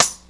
RIM8.WAV